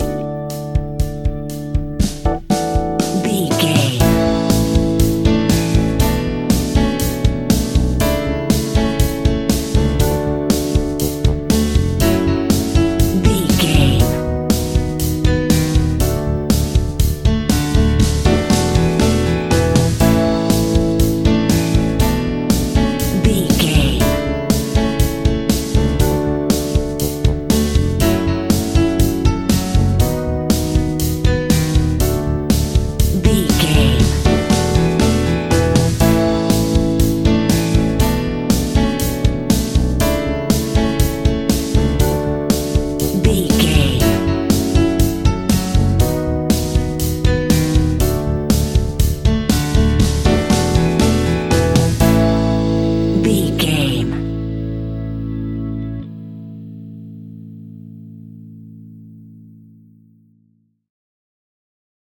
Ionian/Major
pop rock
indie pop
fun
energetic
uplifting
instrumentals
upbeat
groovy
guitars
bass
drums
piano
organ